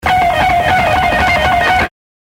(All MP3 examples were played on a guitar tuned down one half step.)
That's all on the E string.